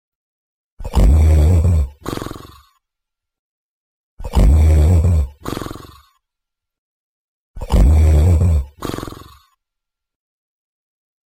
鼾声.mp3